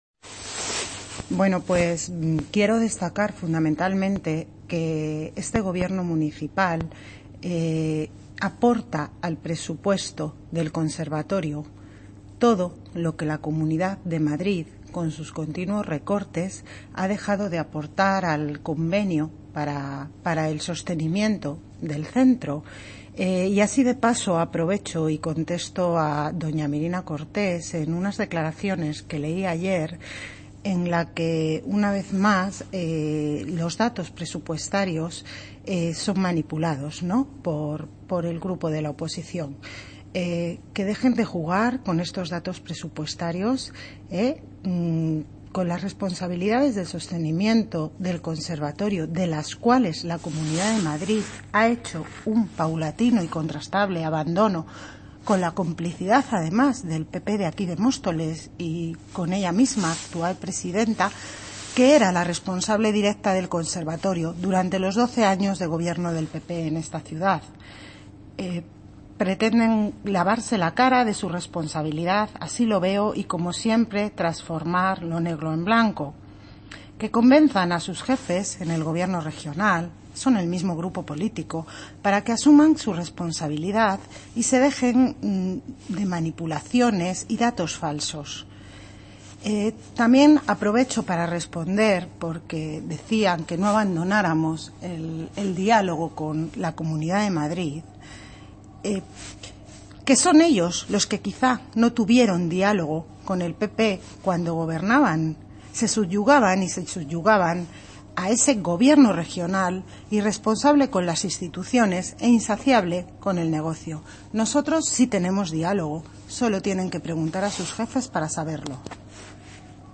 Audio - Isabel Cruceta (Concejal de Educación) Sobre Conservatorio